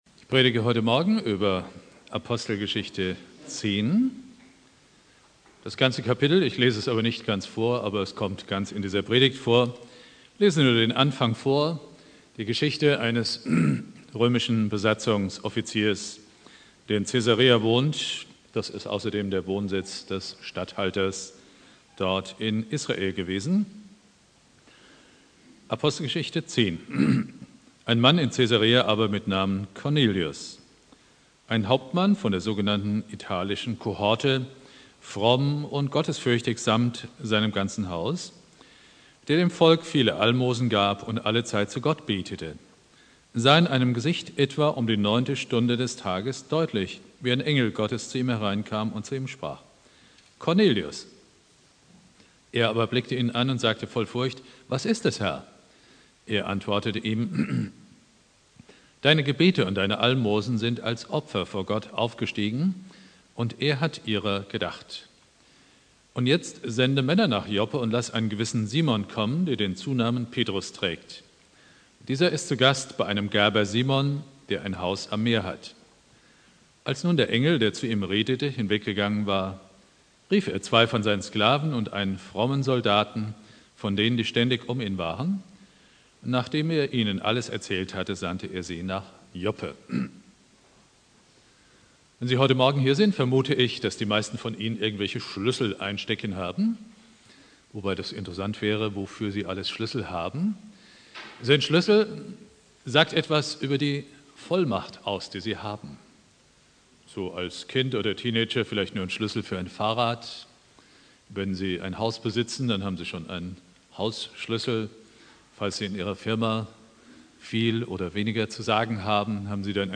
Predigt
Ostermontag Prediger